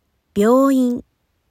Click the audio bar to hear how they sound differently!